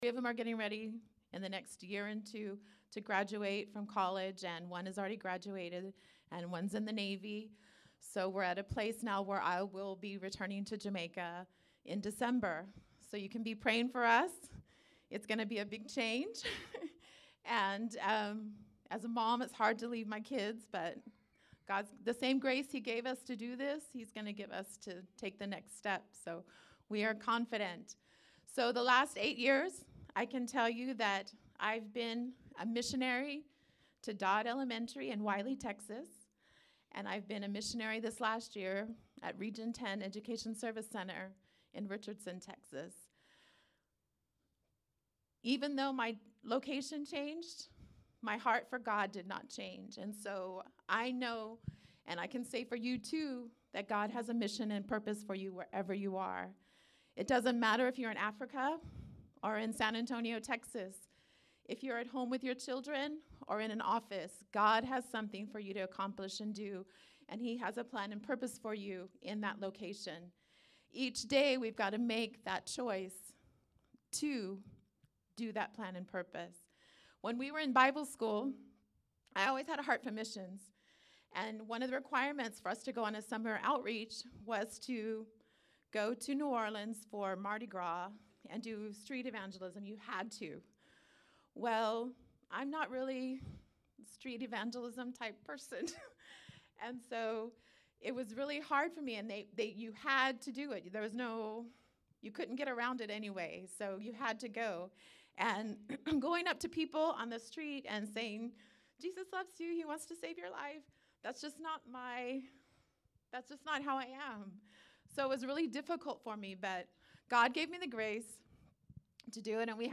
Sermons | Living Faith Church